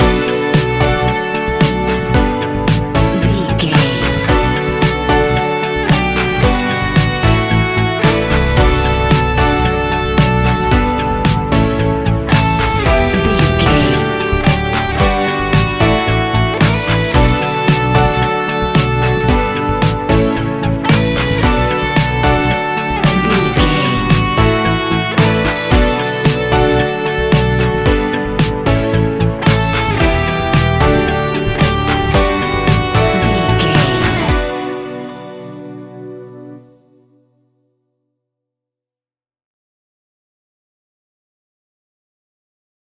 Ionian/Major
D♭
ambient
electronic
new age
chill out
downtempo
synth
pads
drone